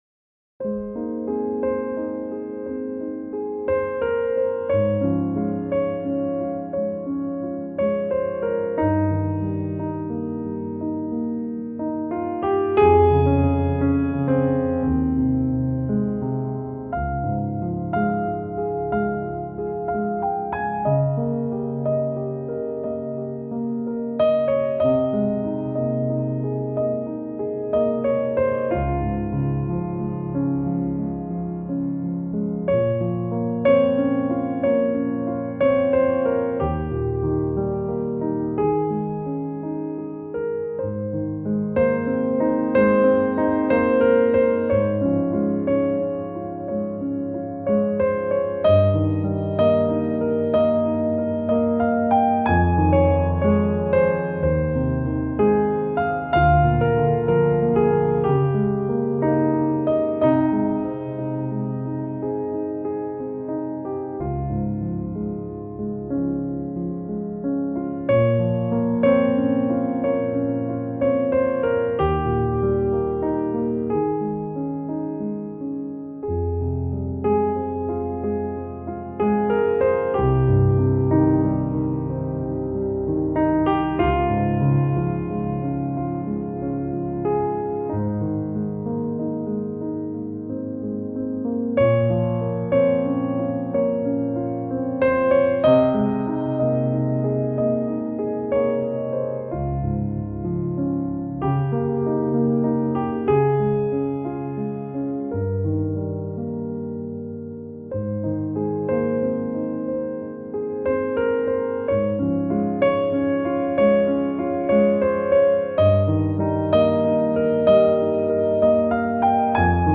آرامش‌بخش